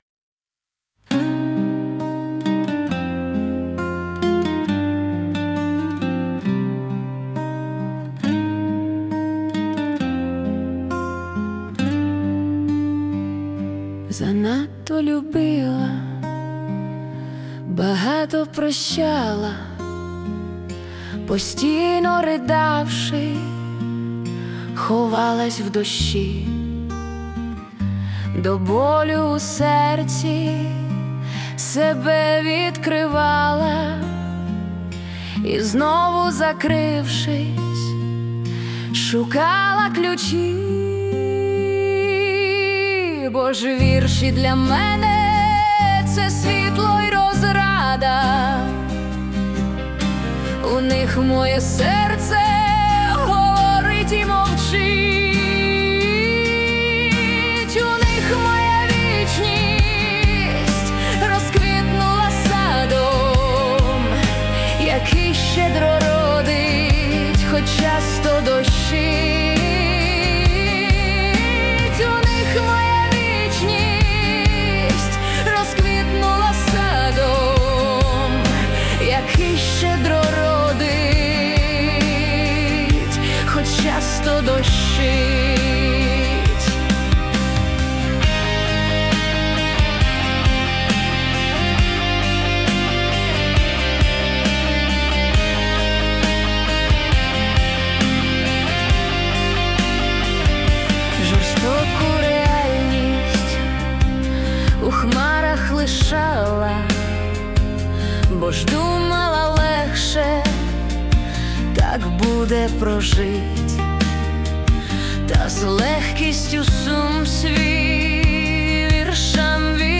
( Музика та виконання ШІ)
СТИЛЬОВІ ЖАНРИ: Ліричний